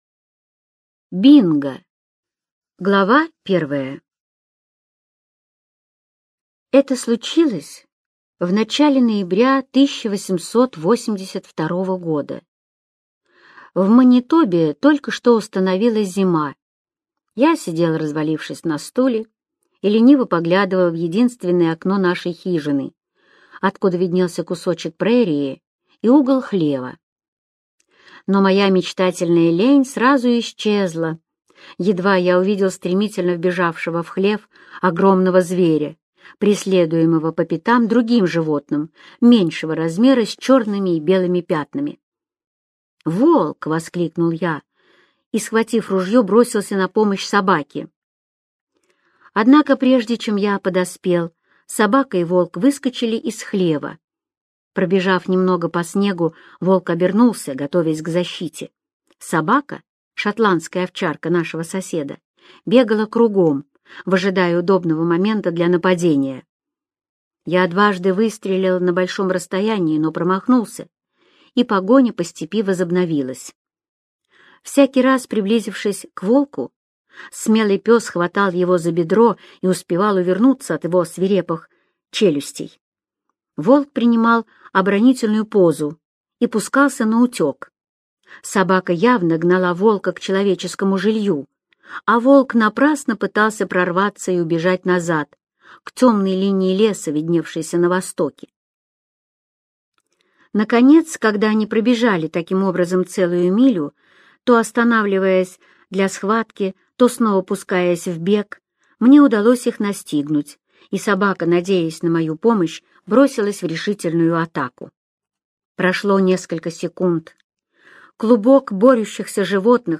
Бинго - аудио рассказ Эрнеста Сетона-Томпсона - слушать онлайн